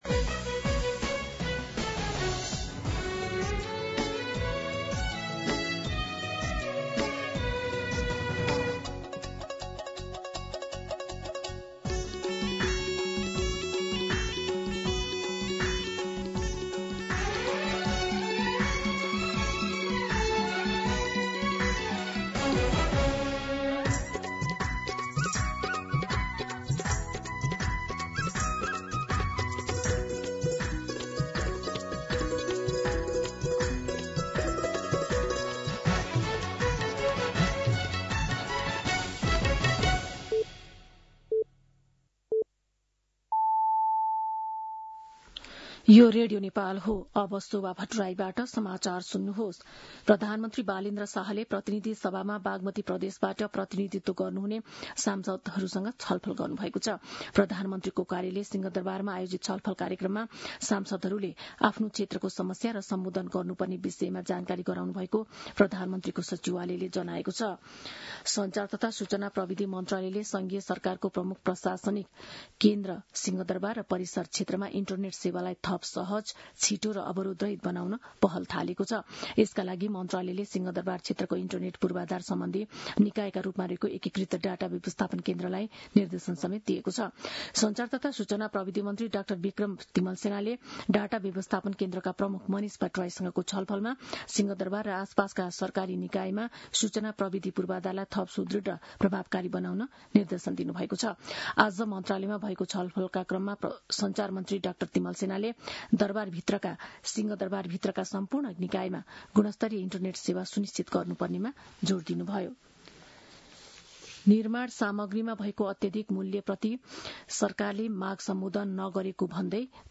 दिउँसो ४ बजेको नेपाली समाचार : ३० चैत , २०८२
4pm-News-30.mp3